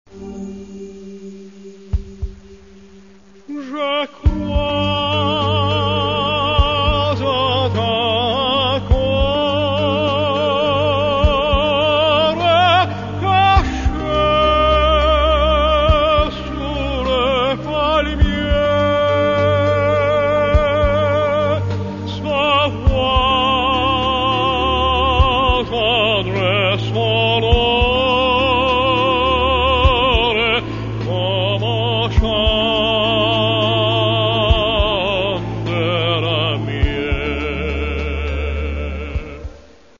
Catalogue -> Classical -> Opera and Vocal